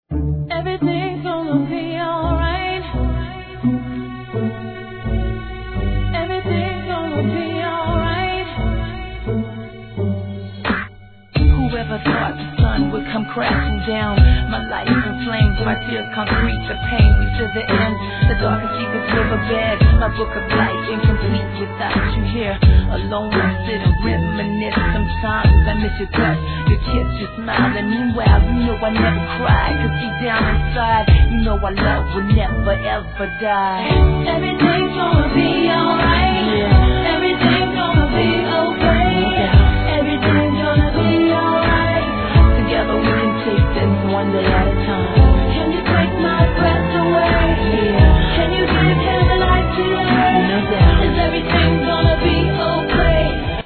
HIP HOP/R&B
クラシックMUSICネタの先駆け!!